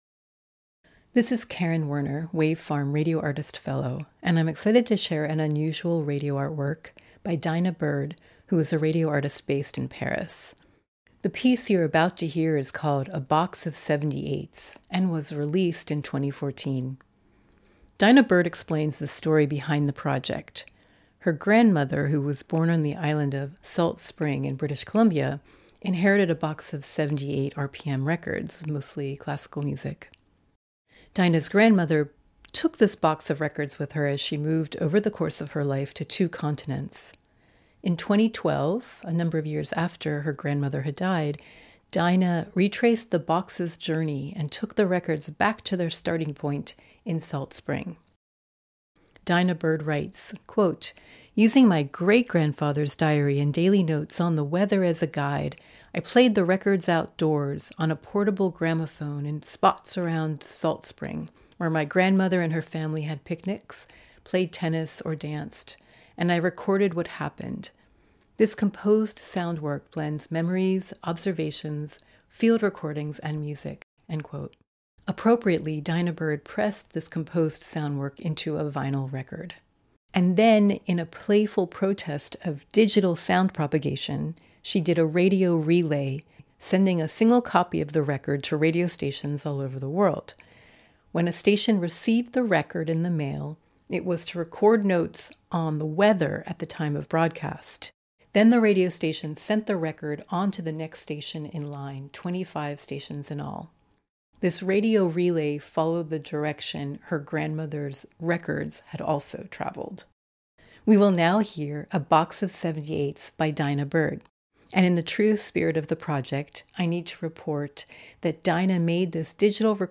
created a noteworthy radio art work called A Box of 78s in 2014.